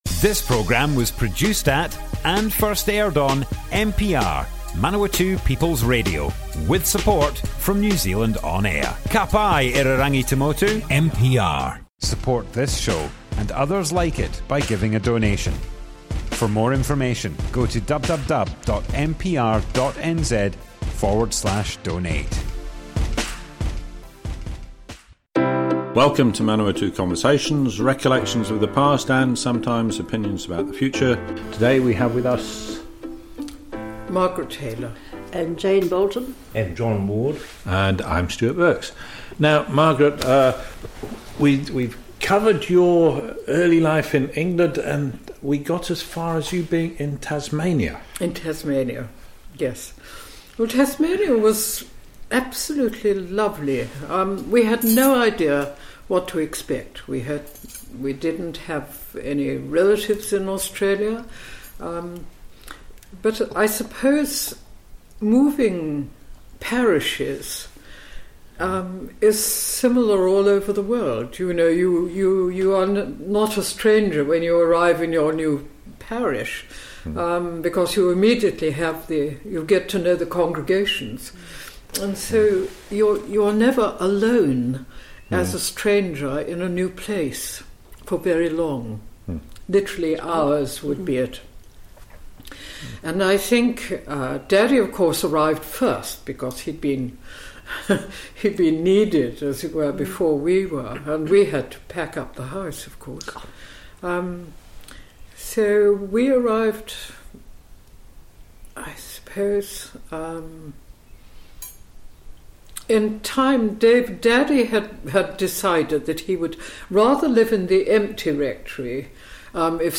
Manawatu Conversations Object type Audio More Info → Description Broadcast on Manawatu People's Radio, 12th January 2021.
oral history